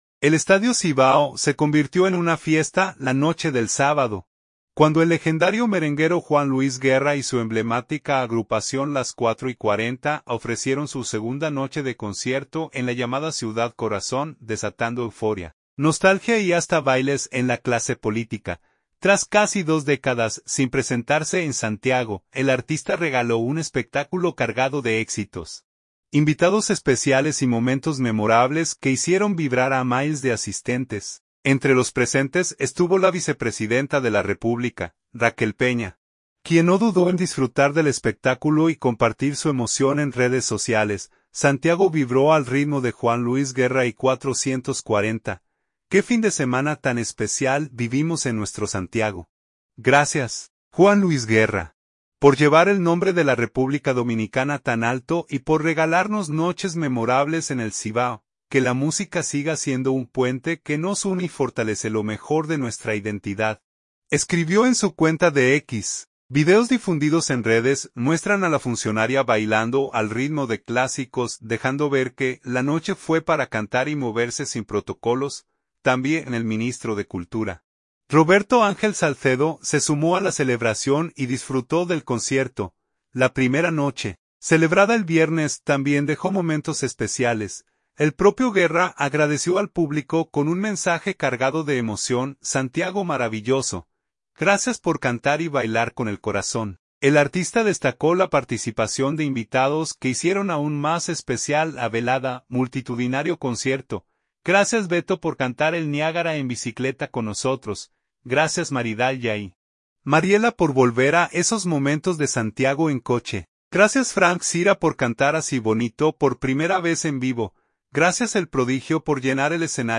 Multitudinario concierto